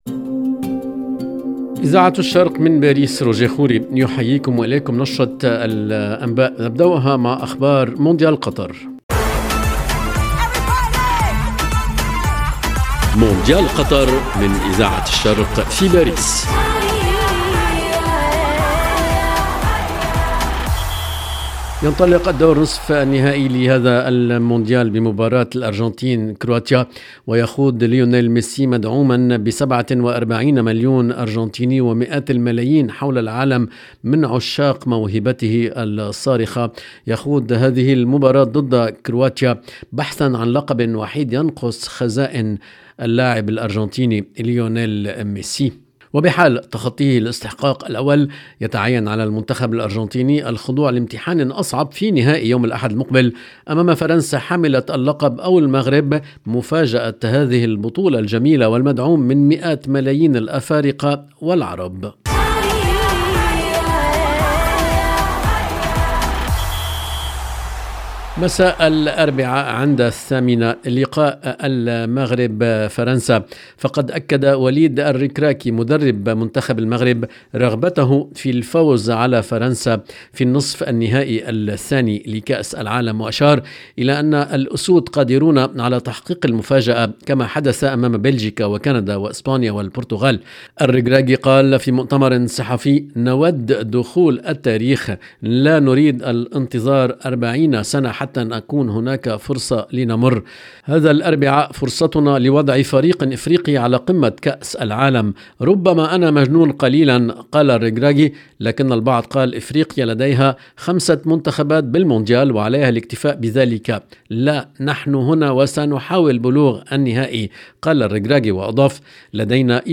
LE JOURNAL EN LANGUE ARABE DU SOIR DU 13/12/22